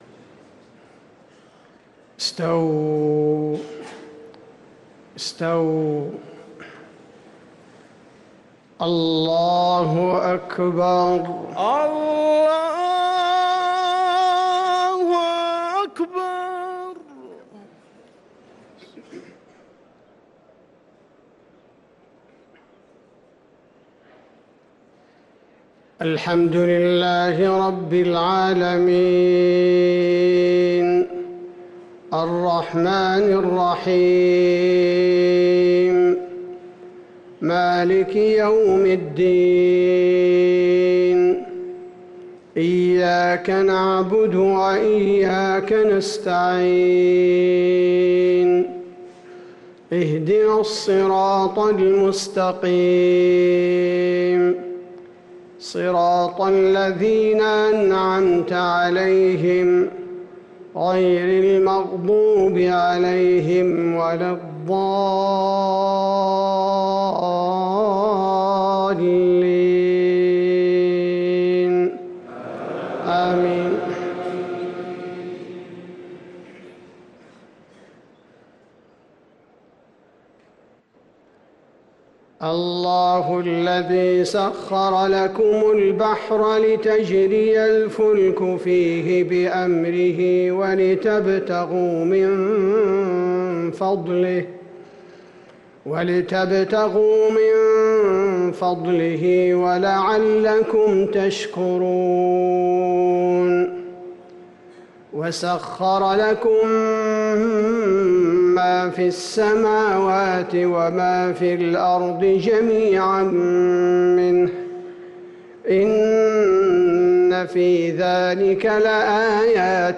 صلاة المغرب للقارئ عبدالباري الثبيتي 15 ربيع الآخر 1445 هـ
تِلَاوَات الْحَرَمَيْن .